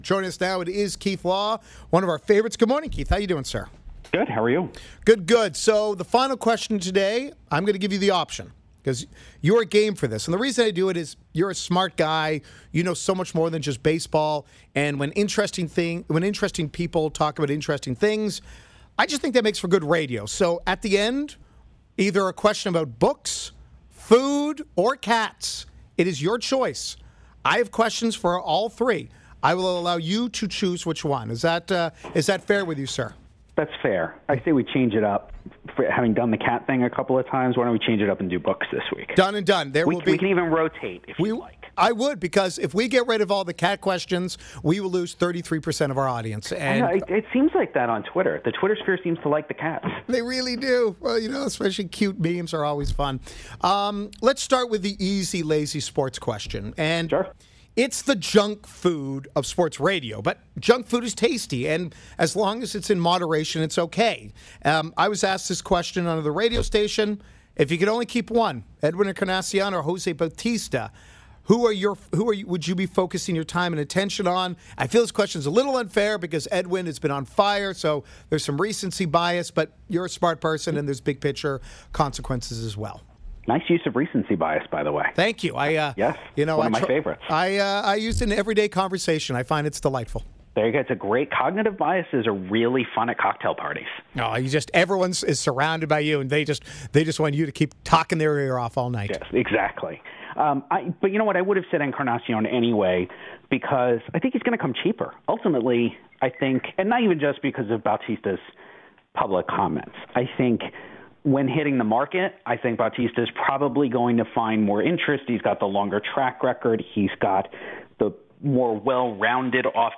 Keith Law of ESPN and Baseball Tonight joins Game Day to discuss whether the Toronto Blue Jays will re-sign Jose Bautista and/or Edwin Encarnacion.